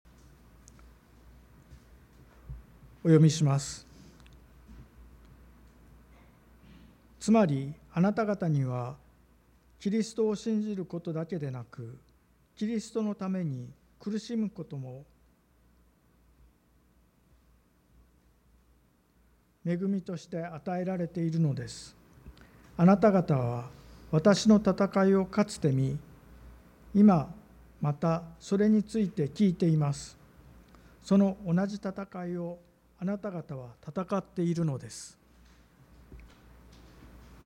【聖書箇所朗読】